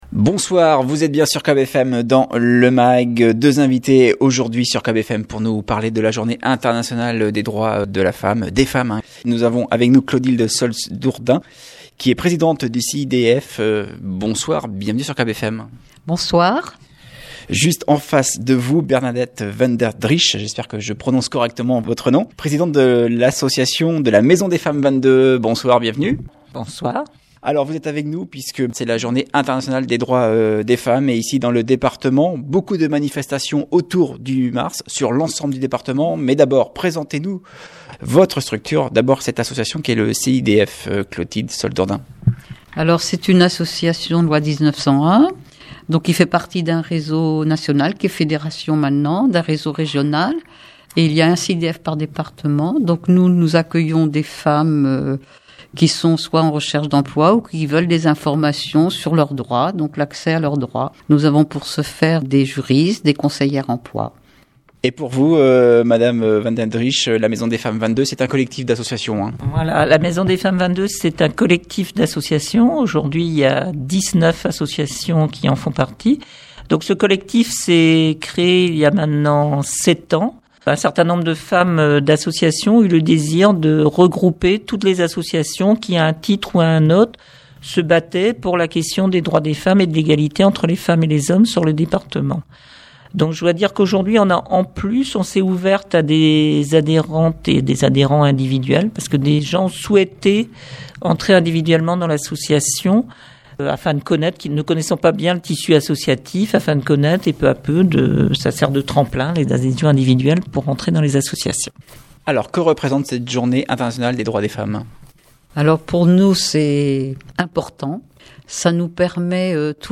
Invitées du Mag hier soir